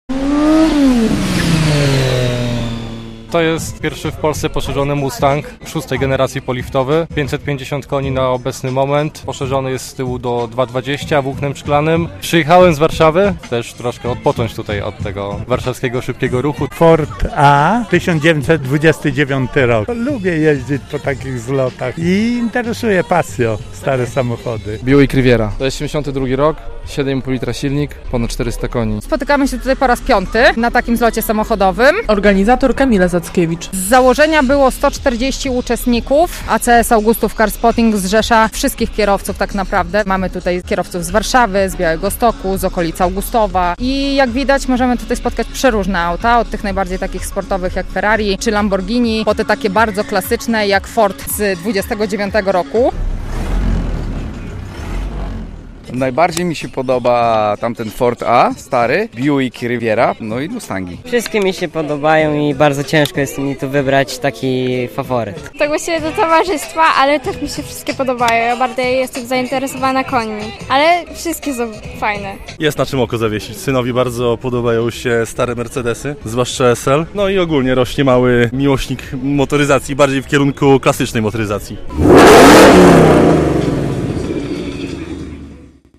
Zlot samochodowy w Augustowie - relacja